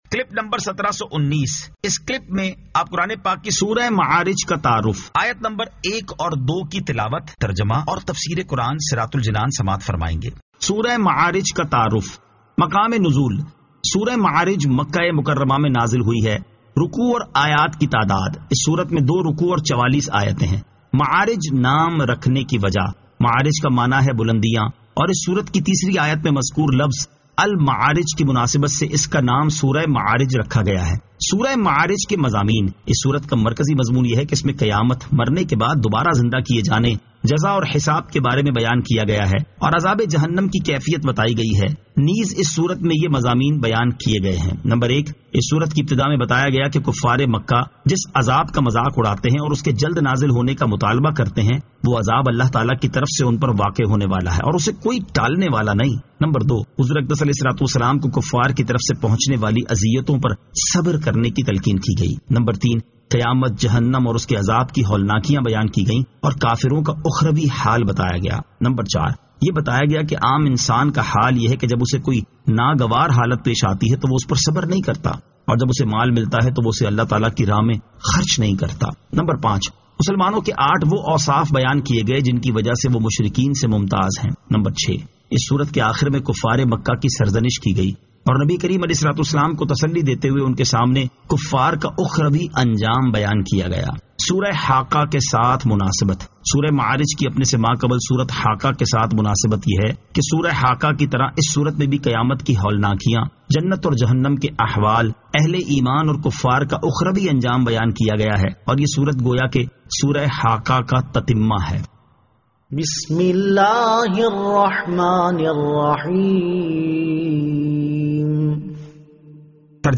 Surah Al-Ma'arij 01 To 02 Tilawat , Tarjama , Tafseer